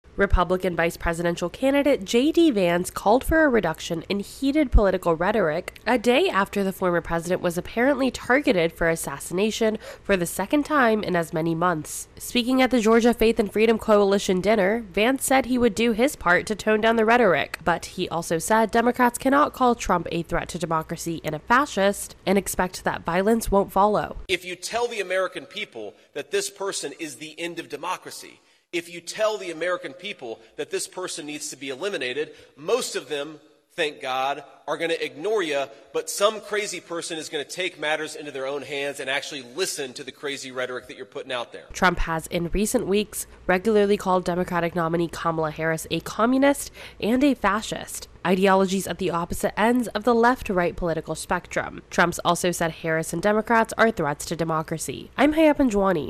reports on JD Vance speaking about political rhetoric at an Atlanta rally.